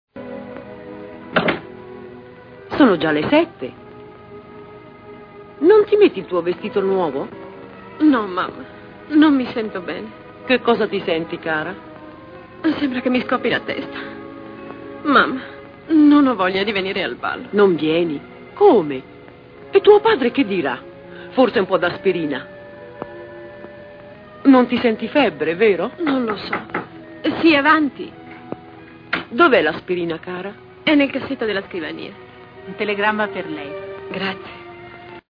nel film "Il sospetto", in cui doppia Dame May Whitty.